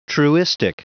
Prononciation du mot truistic en anglais (fichier audio)